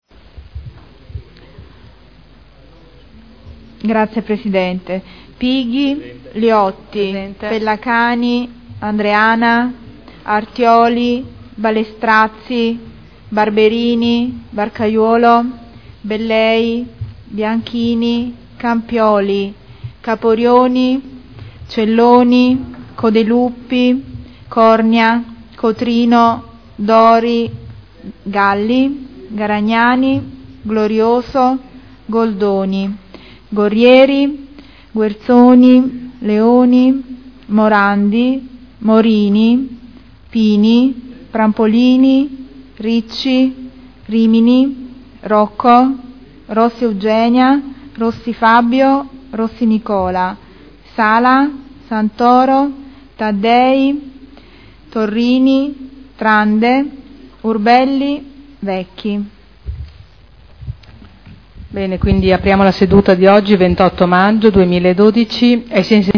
Seduta del 28/05/2012 Appello
Segretario